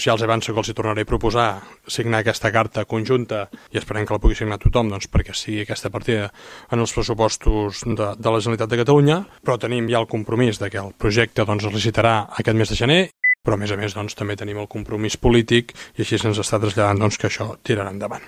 Ahir va demanar unitat al conjunt de plenari, que va validar gairebé de forma unànime el document que actualitza el protocol entre institucions per tirar-lo endavant.